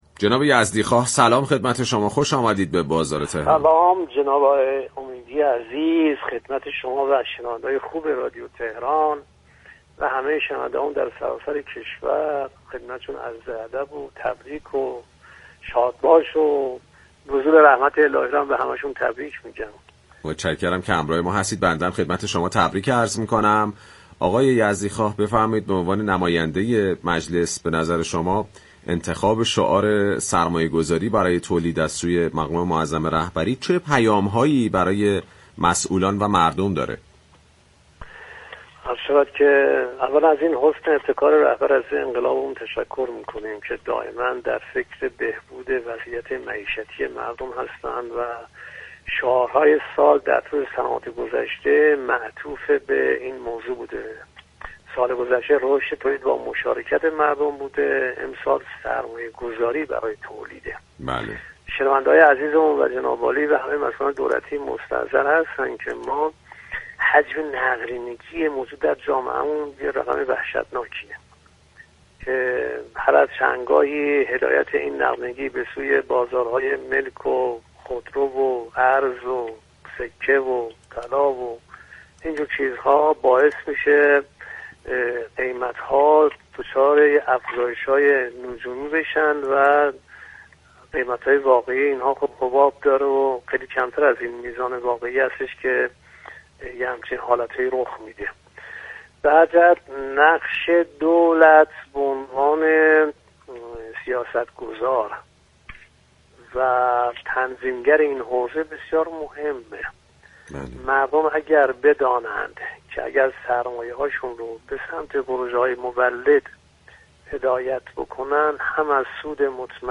به گزارش پایگاه اطلاع رسانی رادیو تهران، سید علی یزدی‌خواه نایب رئیس كمیسیون فرهنگی مجلس شورای اسلامی در گفت و گو با برنامه «بازار تهران» درخصوص پیام شعار سال اظهار داشت: حجم نقدینگی كشور وحشتناك است، هدایت این نقدینگی به سمت بازارهای مختلفی از جمله خودرو، طلا، سكه و ارز باعث تغییرات نجومی و ایجاد حباب در این بازارها می‌شود.